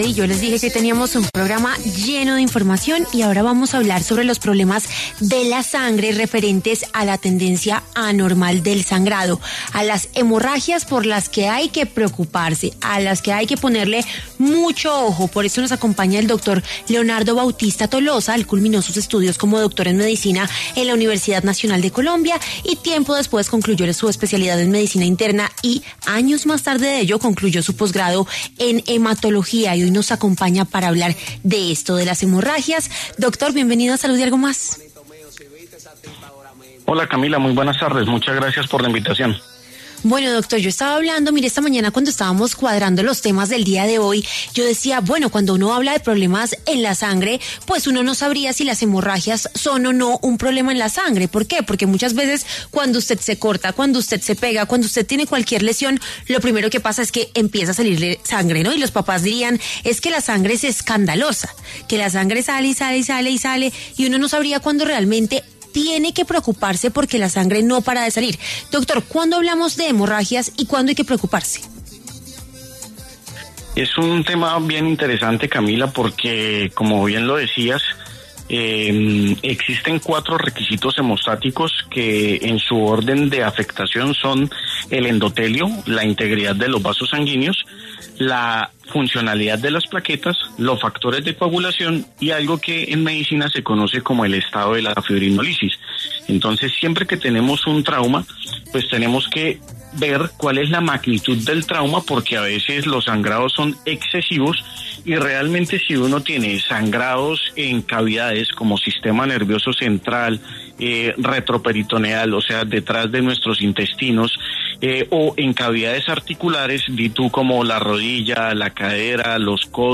médico internista hematólogo, habló en Salud y Algo Más sobre las causas y las recomendaciones sobre las hemorragias.